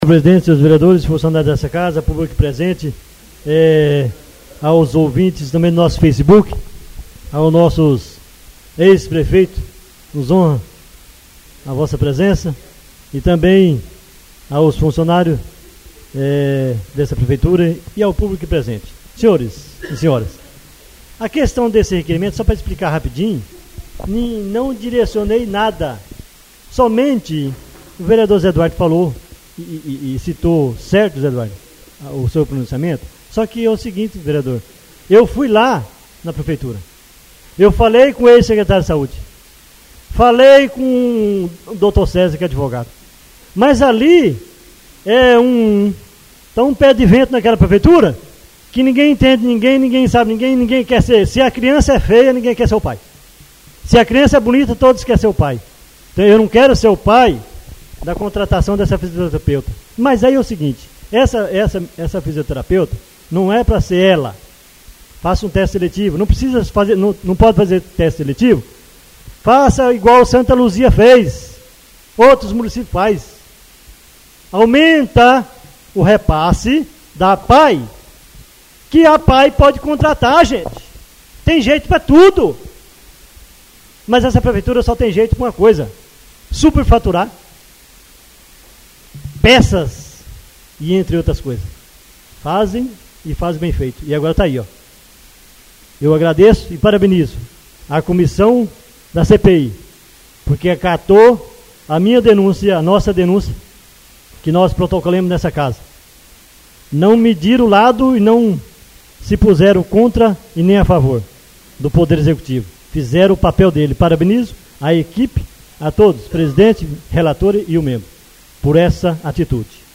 Oradores das Explicações Pessoais (28ª Ordinária da 3ª Sessão Legislativa da 6ª Legislatura)